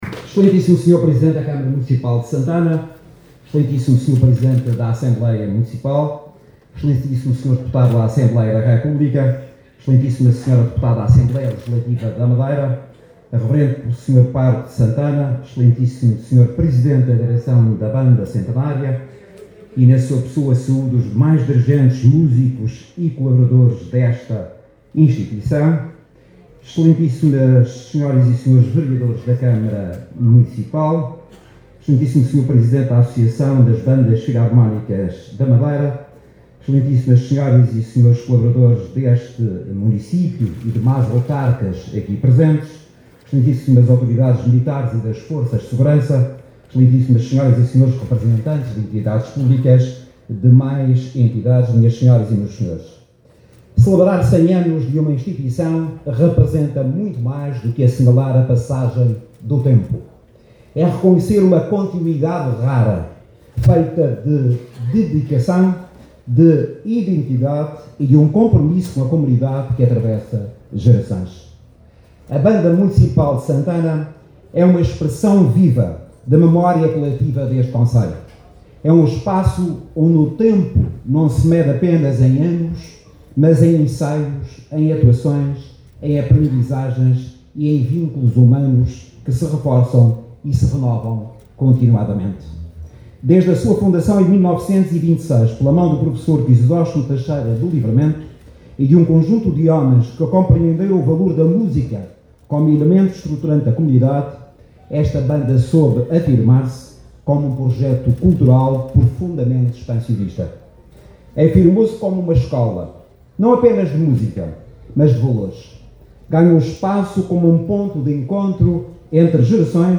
O Secretário Regional de Economia, José Manuel Rodrigues, sublinhou esta tarde, durante a Sessão Solene Comemorativa do Centenário da Banda Municipal de Santana, a importância da identidade e da cultura madeirense como fundamentos da Autonomia e instrumentos essenciais de desenvolvimento da Região.